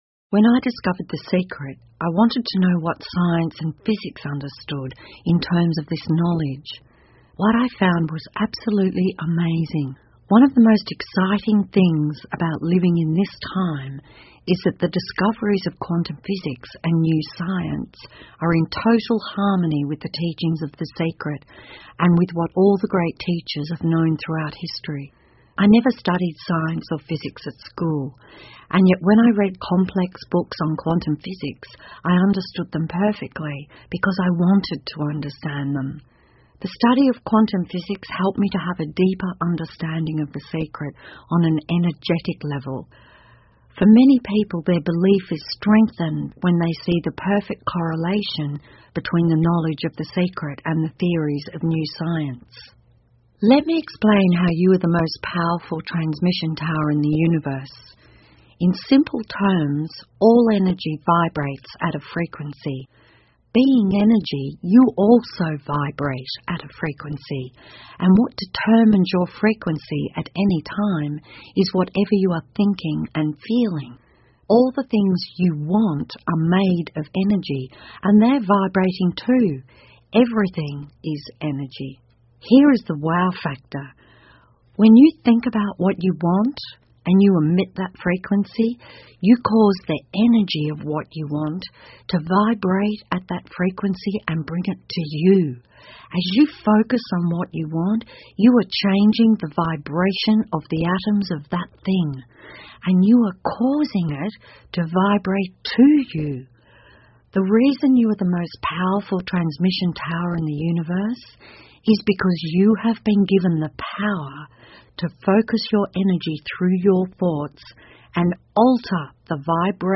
英文有声畅销书-秘密 4-09 When I Discovered The Secre 听力文件下载—在线英语听力室